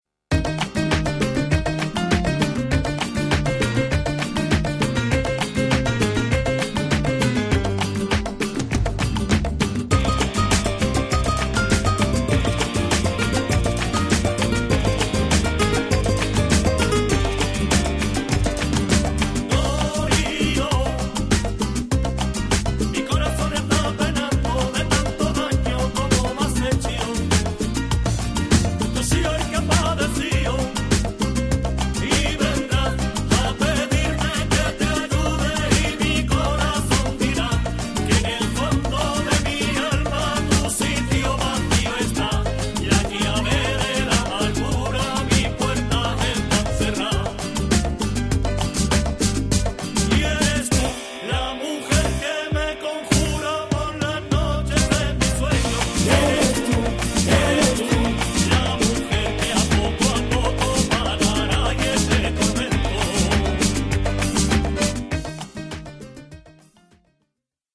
最初から最後まで、彼らのルーツ音楽である『ルンバ・フラメンコ』に対する敬意・愛が溢れている！